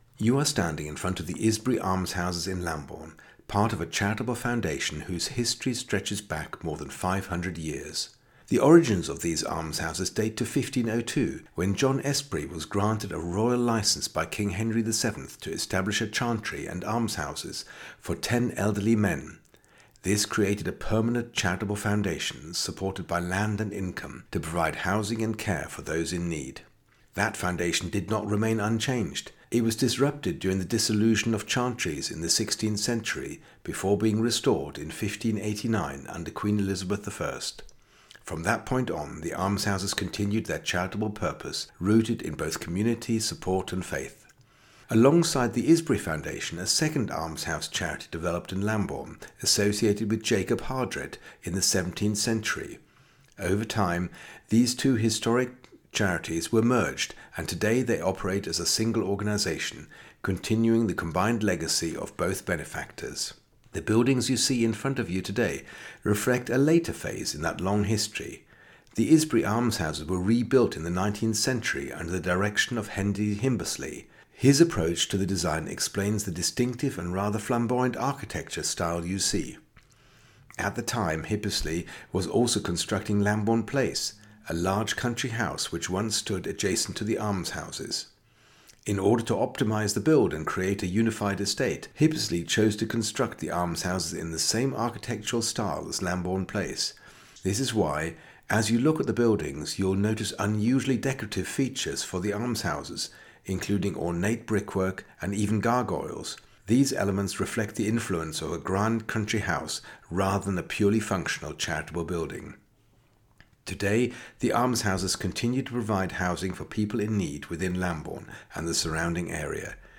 Scan the code and you are taken straight to a short, three minute audio guide.
Just a clear human voice explaining what you are looking at, as if a knowledgeable local guide were standing beside you.